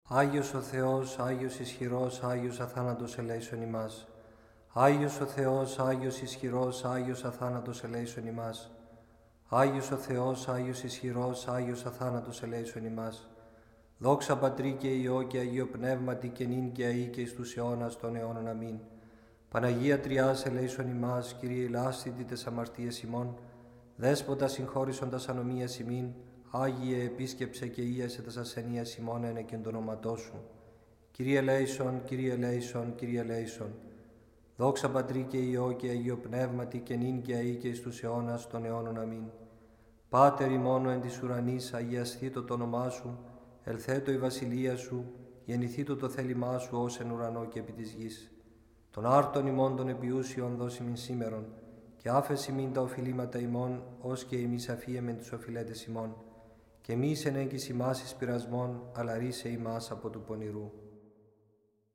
• Категория: Хор разных голосов